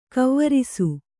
♪ kavvarisu